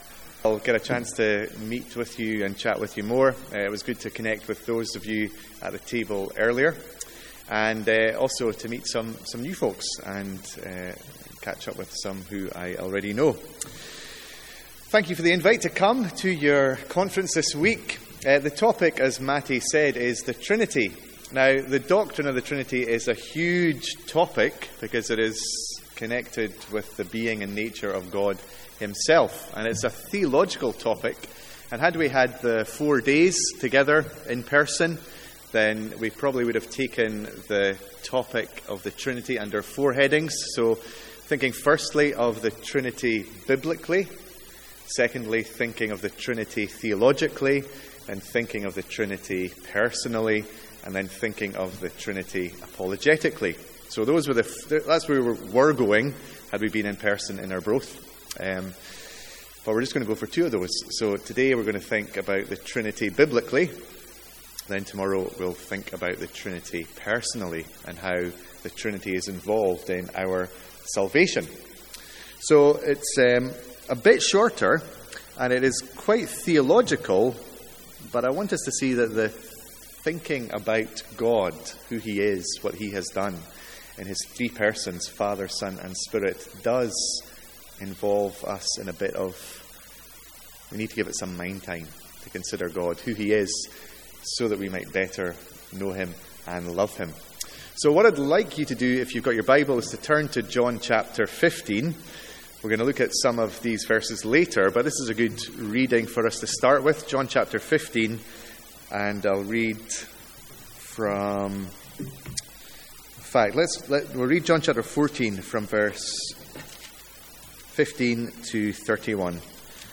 From our Mid Year Conference.